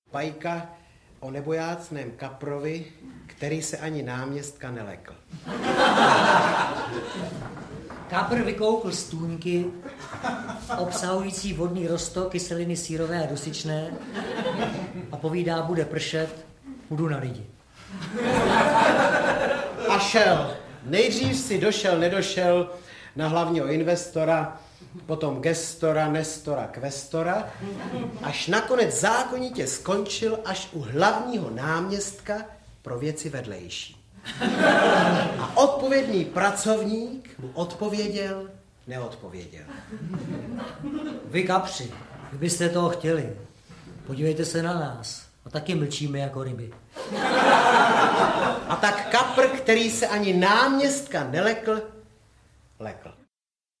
Večer s bratry Justy audiokniha
Ukázka z knihy